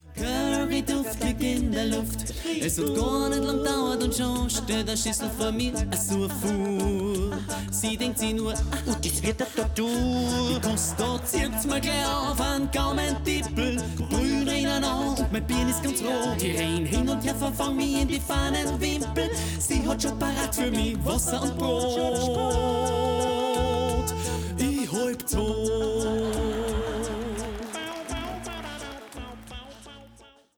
a cappella-Konzertpackage